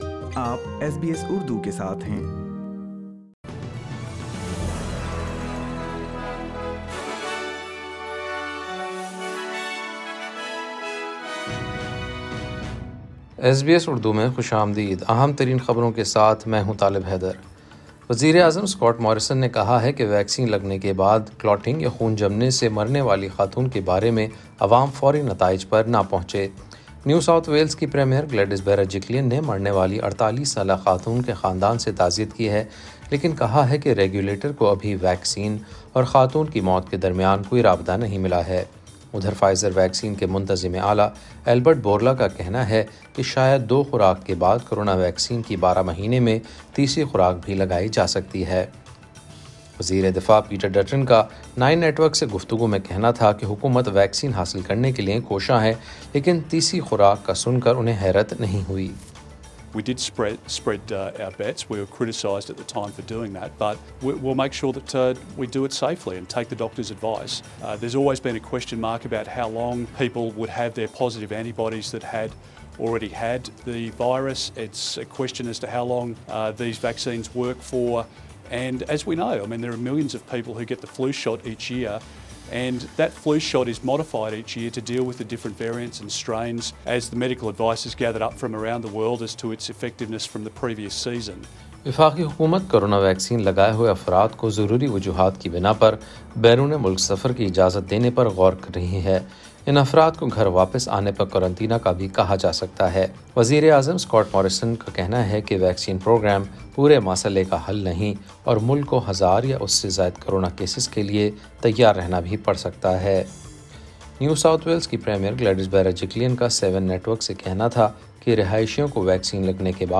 SBS Urdu News 16 April 2021